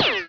Bulletr2.ogg